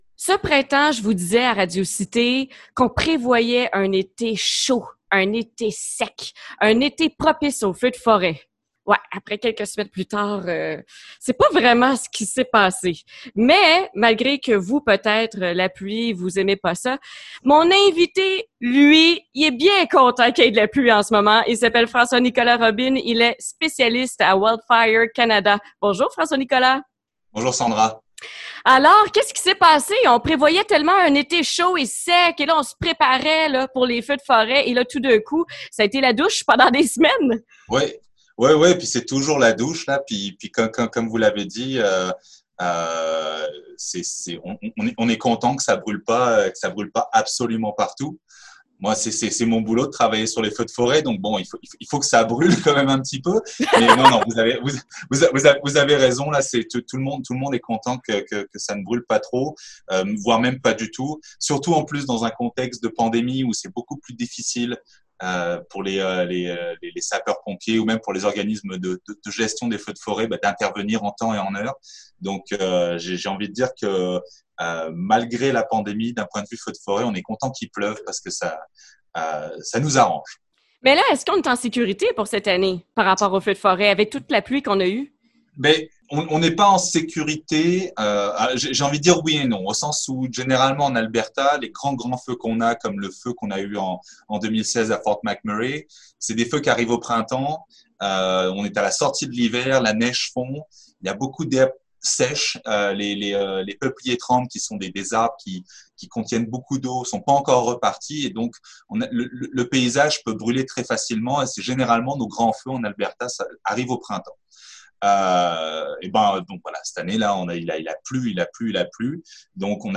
entrevue_feuxdeforet_full.mp3